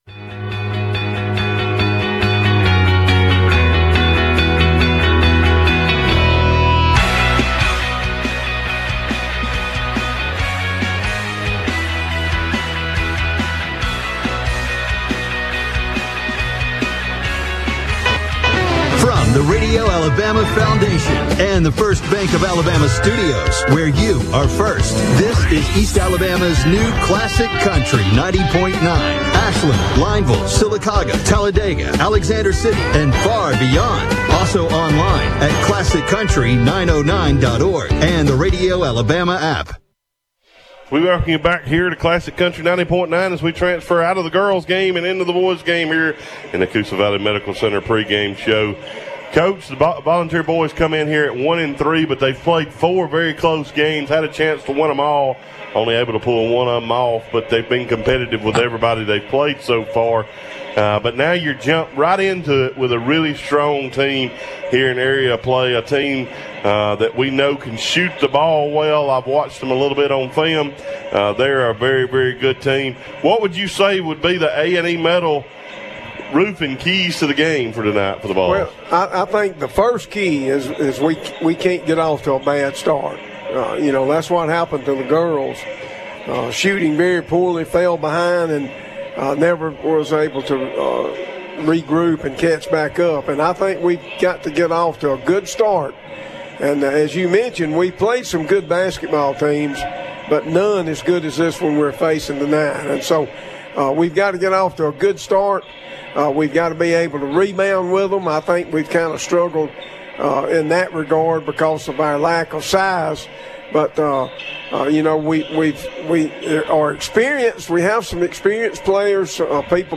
(Boys Basketball) Clay Central vs. Briarwood